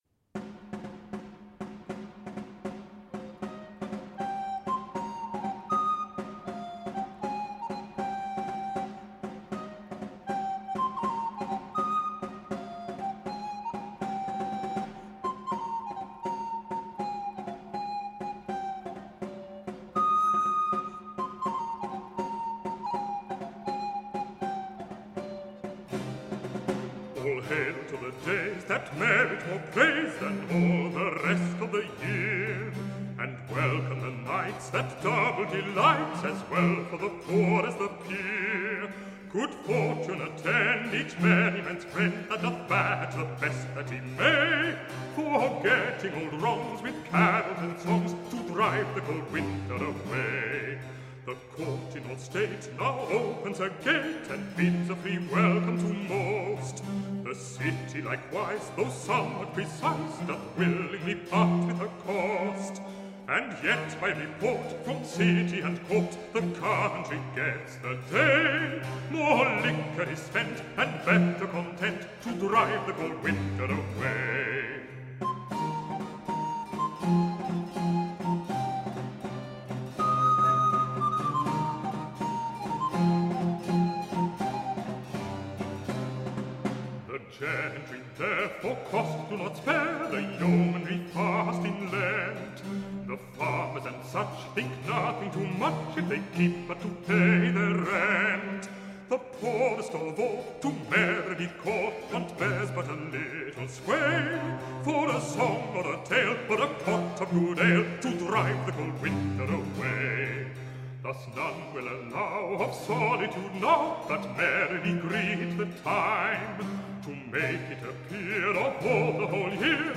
This is our annual holiday special, featuring music, poetry, stories, and recipes!